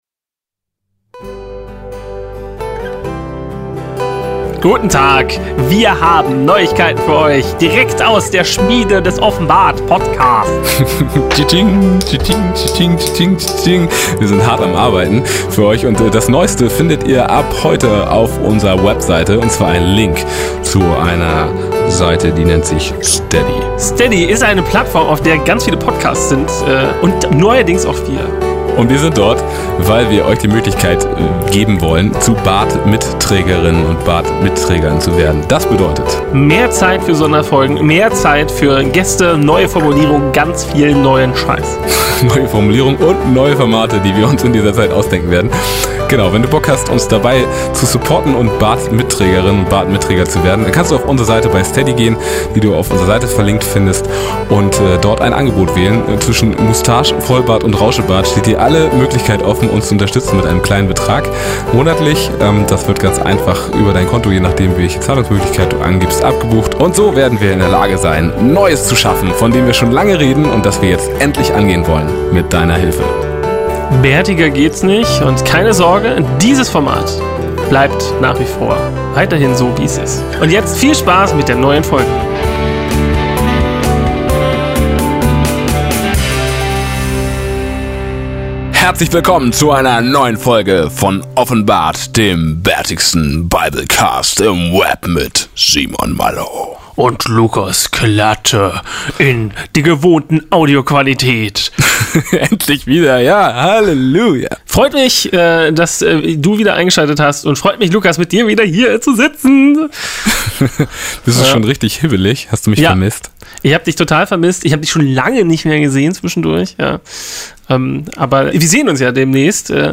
Und dazu singende Gitarren